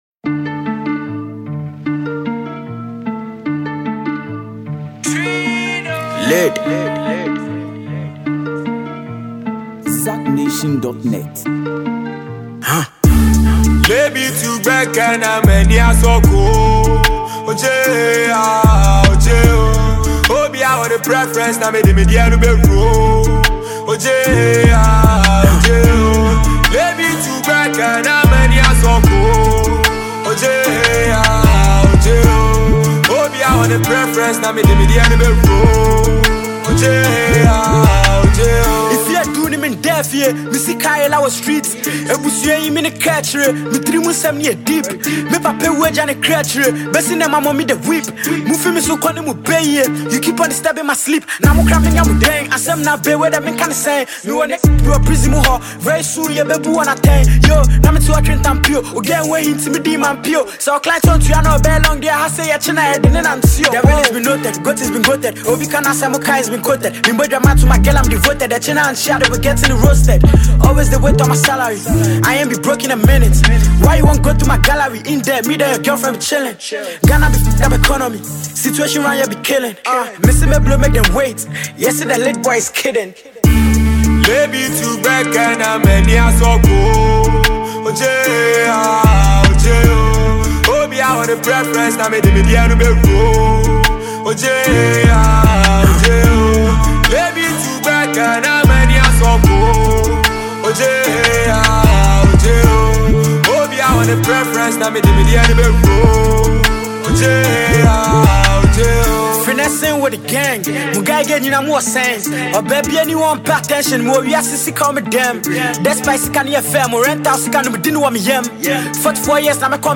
a Ghanaian talented singer